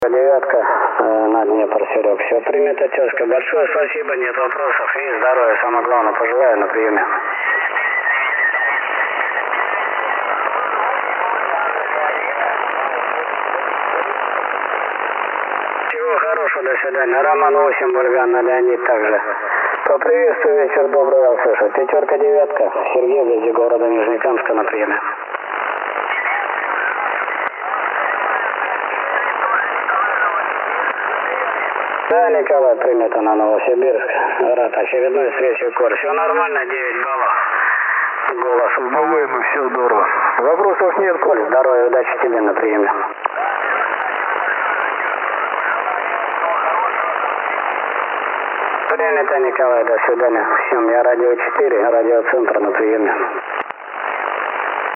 PRE был включен. Все записи с линейного выхода.
В AM и SSB на слух всё более-менее чисто.
SSB.mp3